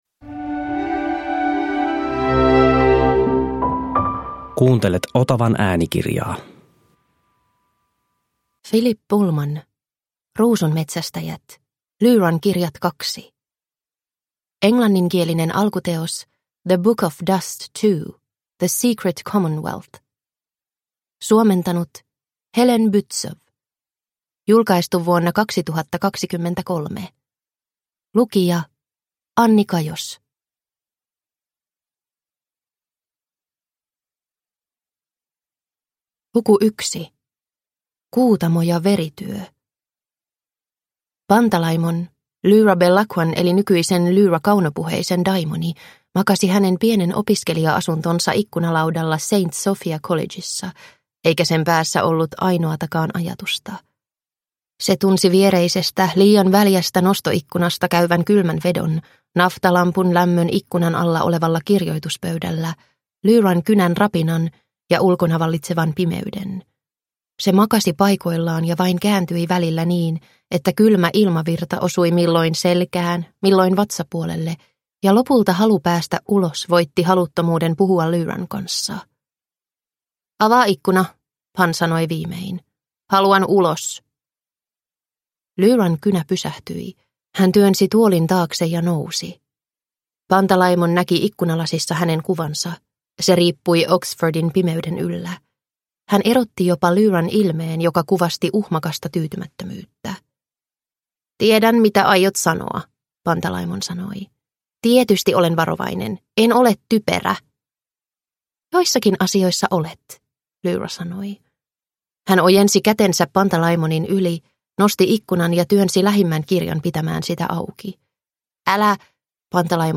Ruusunmetsästäjät – Ljudbok